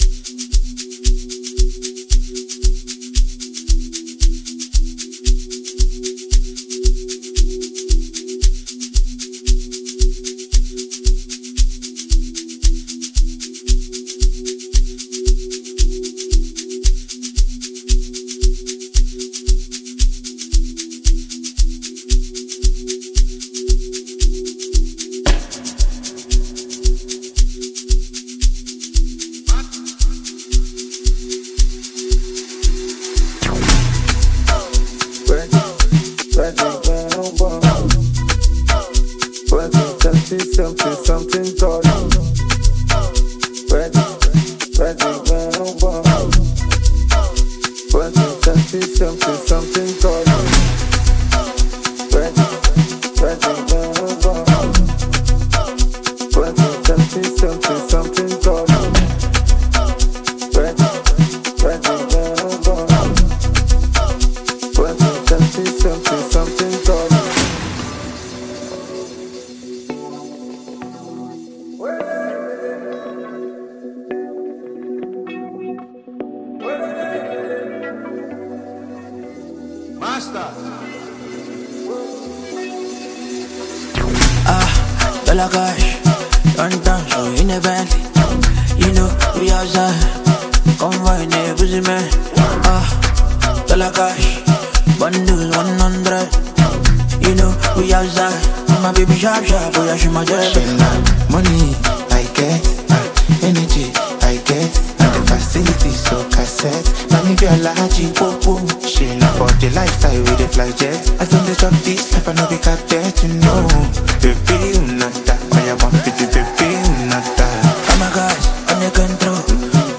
Afrobeats
His energetic delivery adds a vibrant edge to the song.
blends infectious rhythms with polished sound engineering